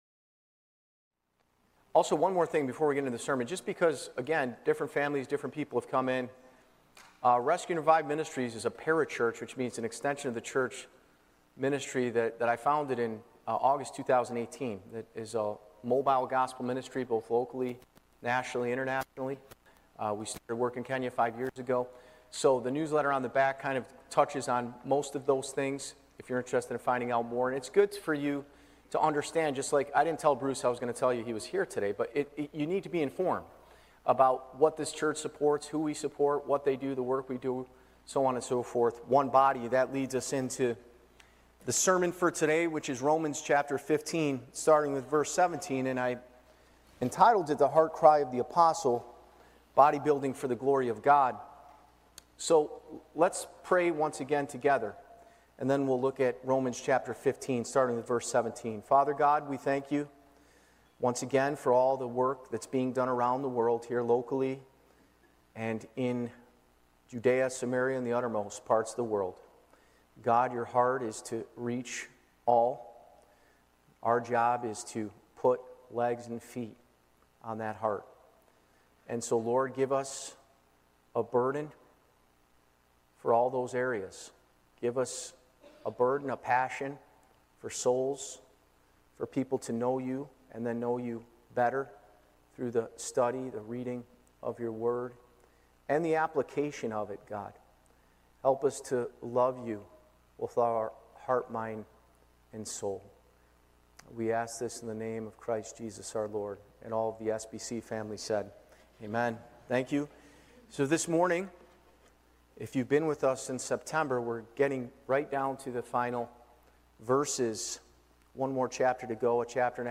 Romans 15:17-33 Live Recording